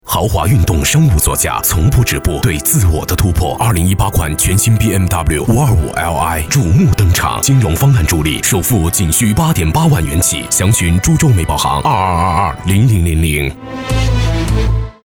• 5央视男声4号
宝马广告-深沉大气